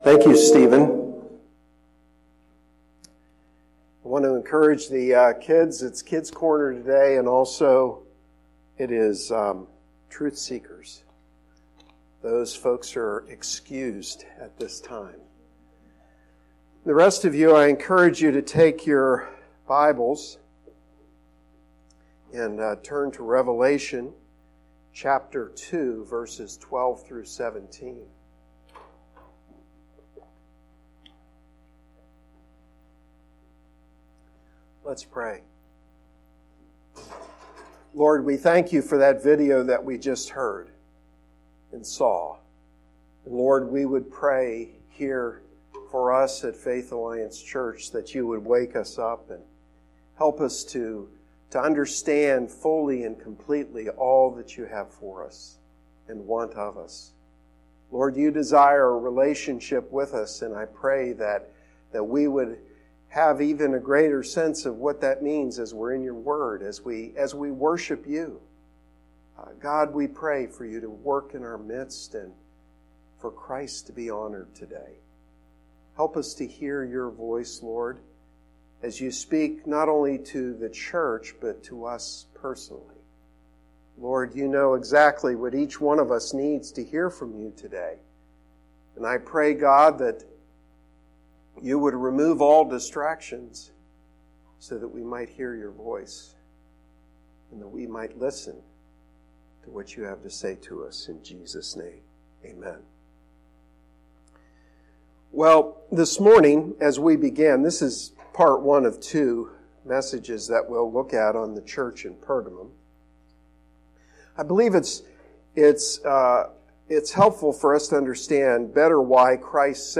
Sermon-2-24-19.mp3